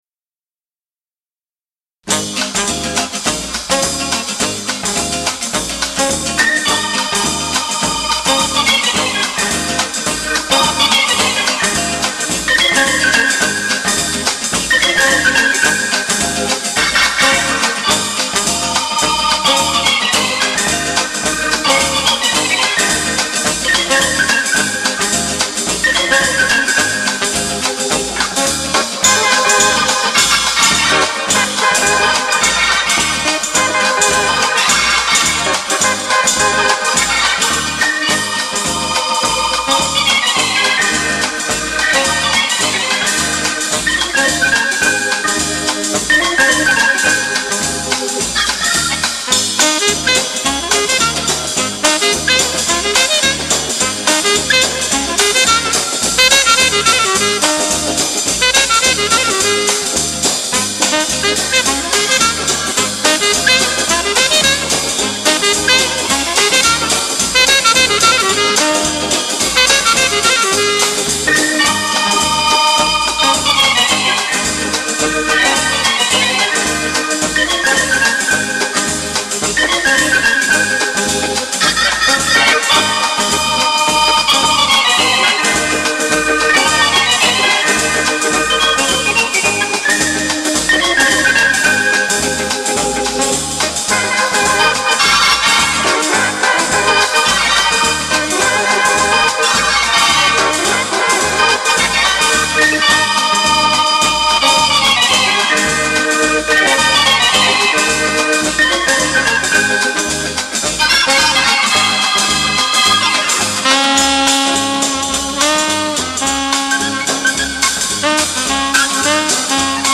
LATIN-FOX